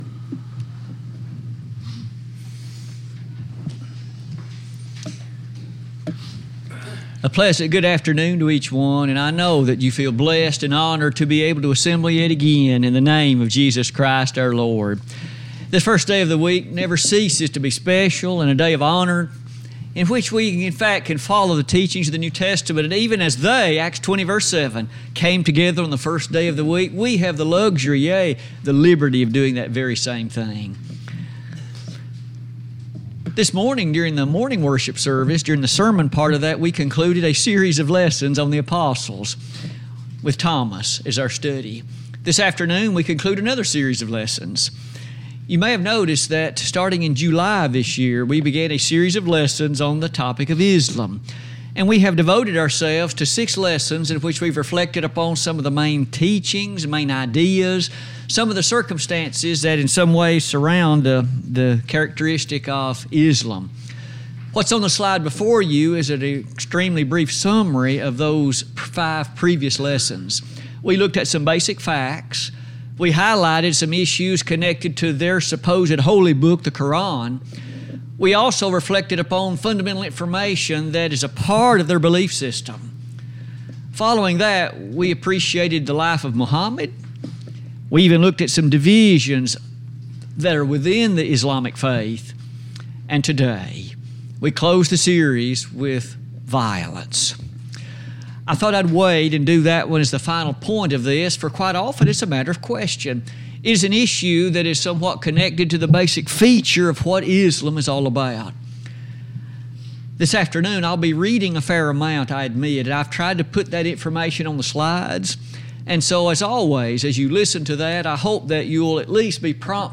Sermons Recordings